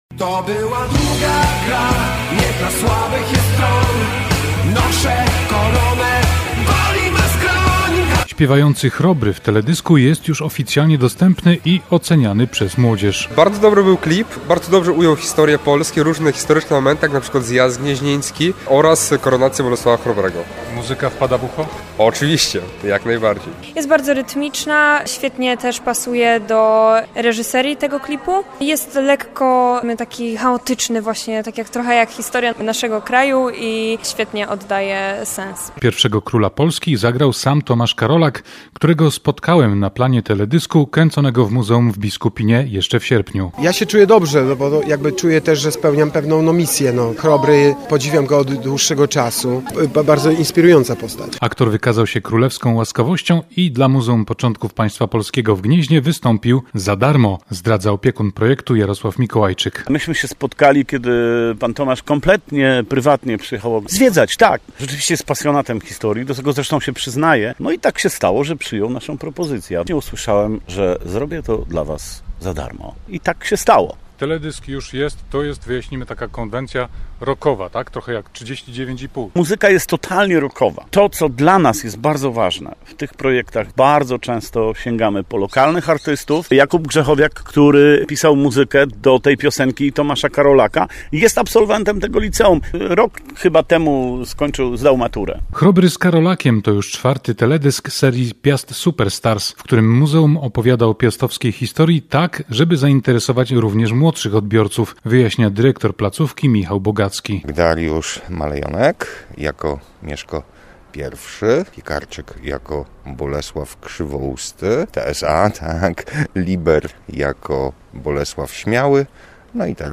- mówi młodzież, która widziała już teledysk.